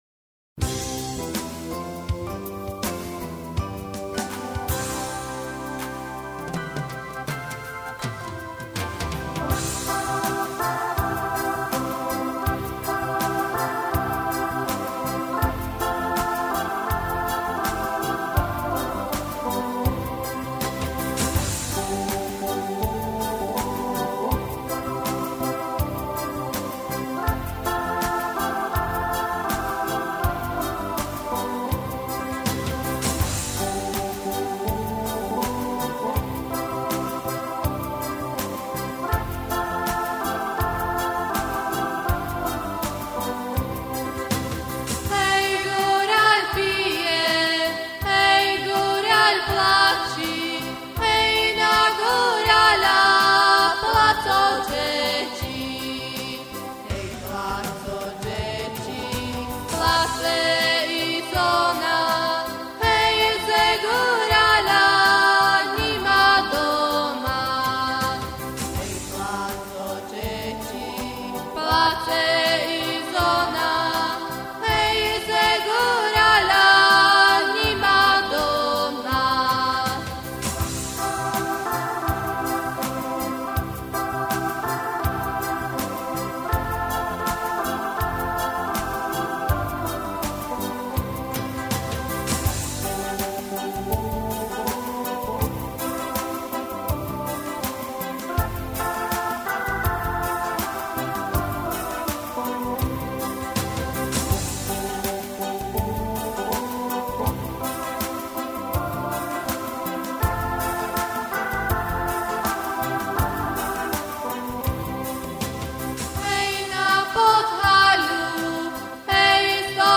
V modernom prevedení:-))) .